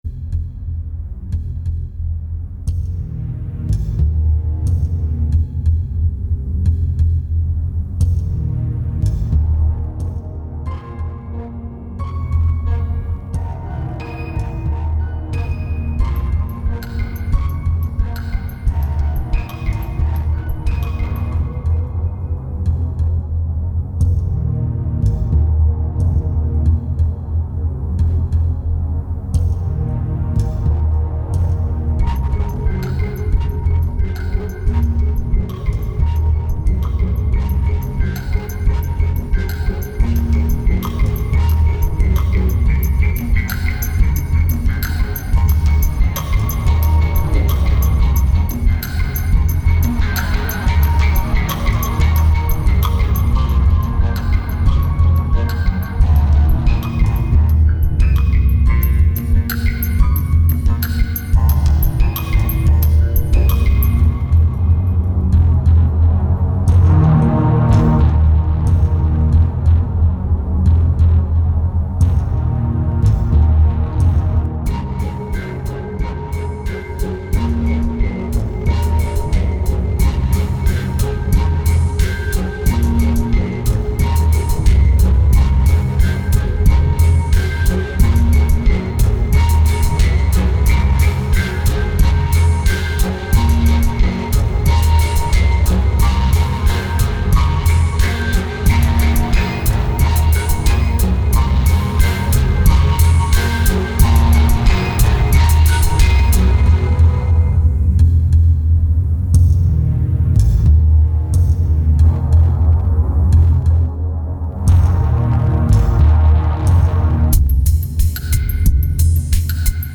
2124📈 - -30%🤔 - 90BPM🔊 - 2009-01-30📅 - -240🌟